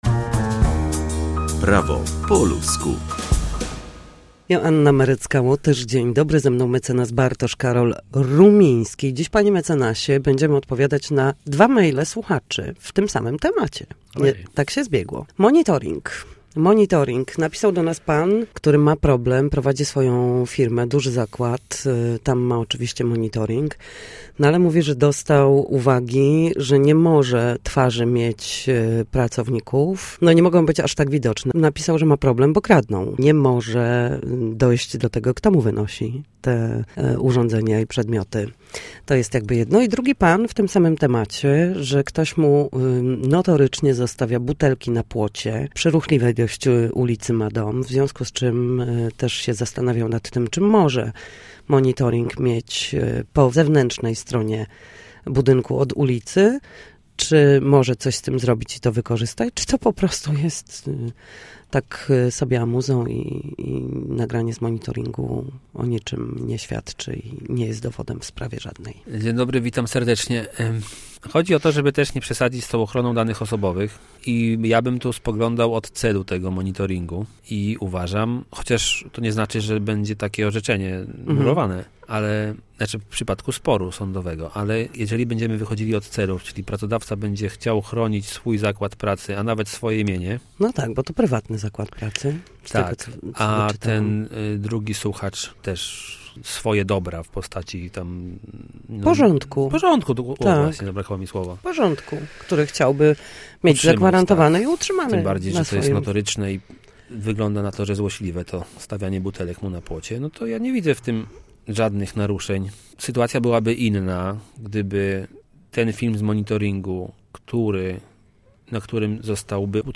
W każdy piątek o godzinie 7:20 i 13:40 na antenie Studia Słupsk przybliżamy państwu meandry prawa. Nasi goście, prawnicy, odpowiadają na jedno pytanie dotyczące zachowania w sądzie czy podstawowych zagadnień prawniczych.